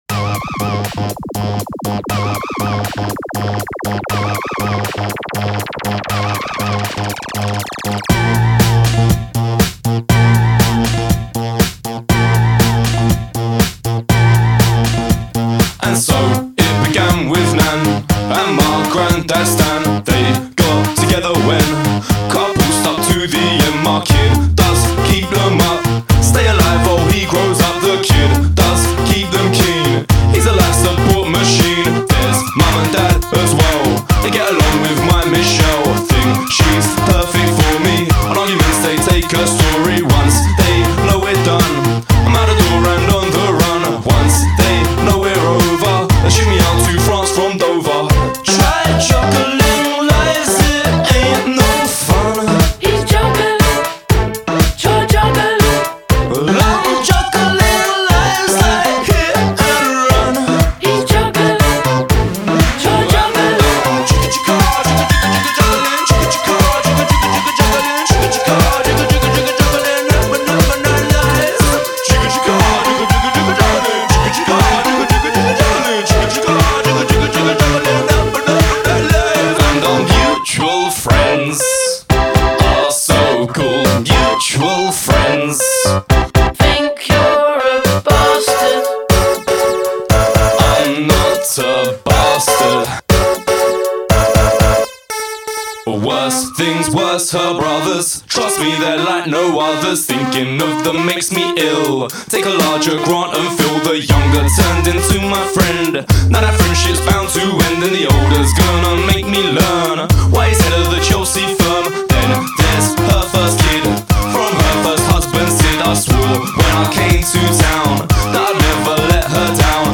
a trio from the UK with a fun electro/house/hip hop track.